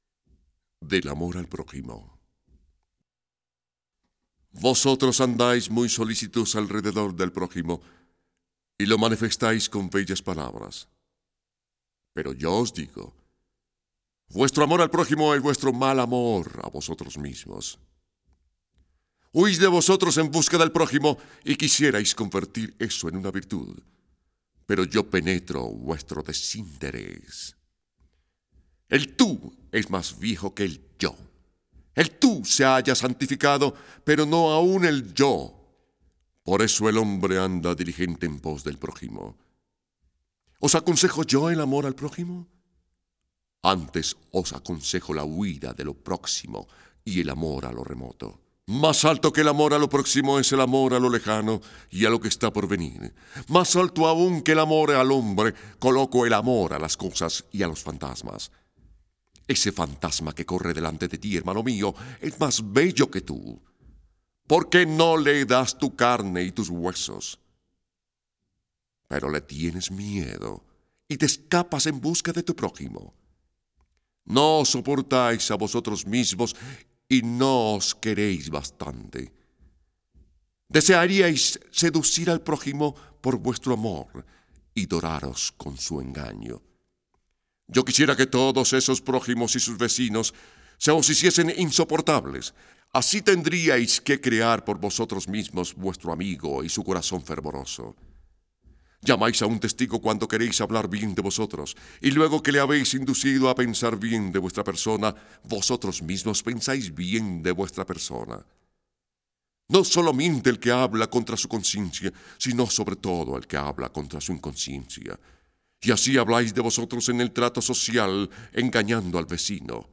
Libros Gnosticos en Audio Mp3